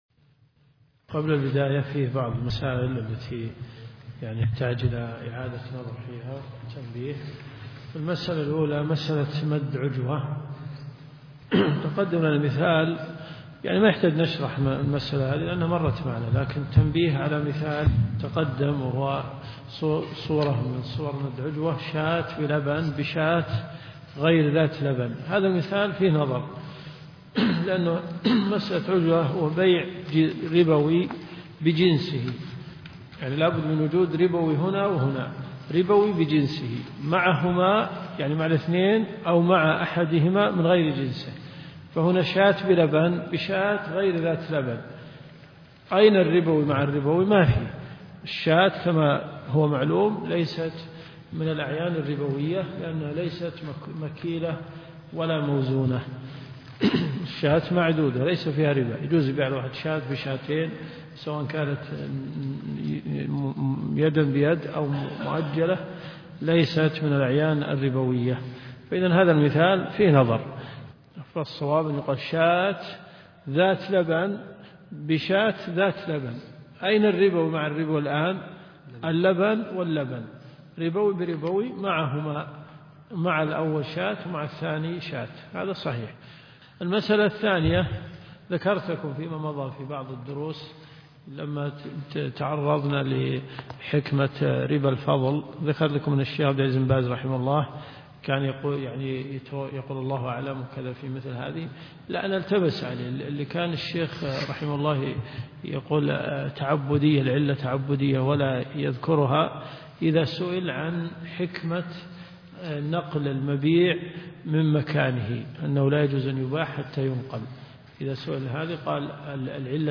دروس صوتيه
جامع البلوي